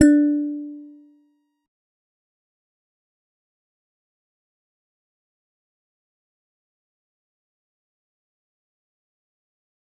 G_Musicbox-D4-mf.wav